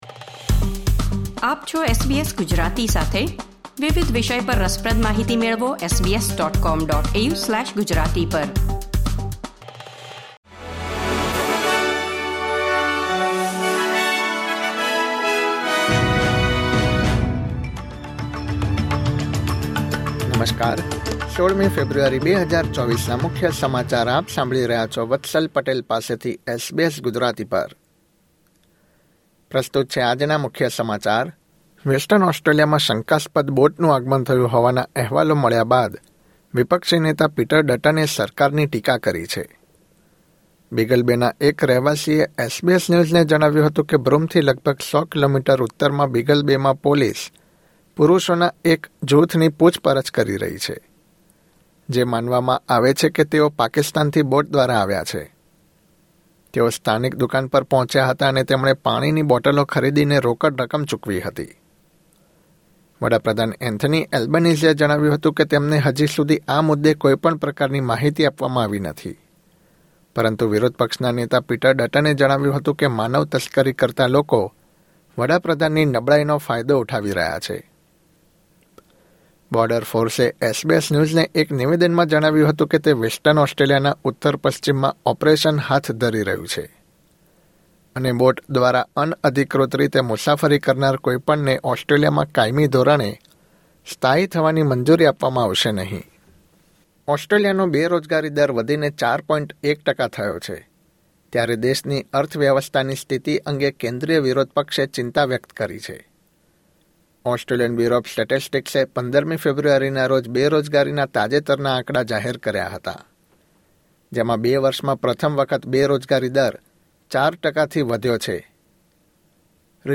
SBS Gujarati News Bulletin 16 February 2024